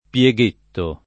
pieghetto [ p L e g% tto ]